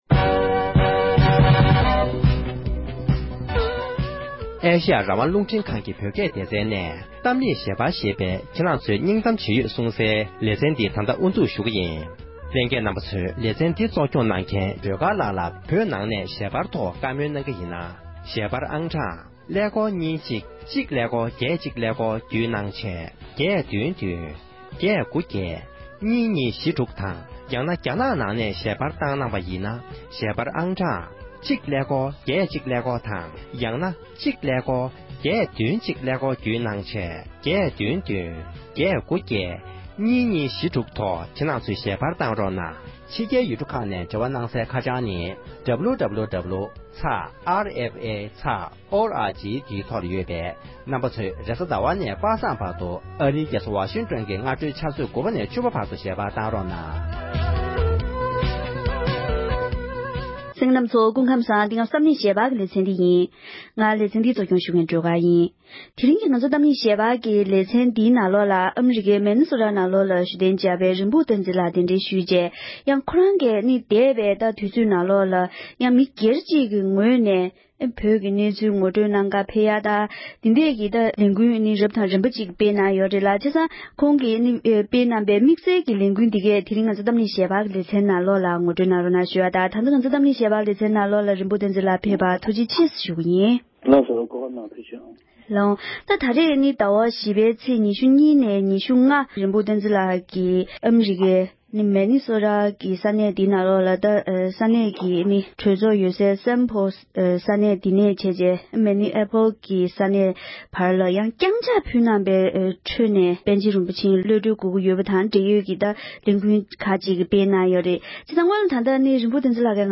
བཀའ་མོལ་ཞུས་པ།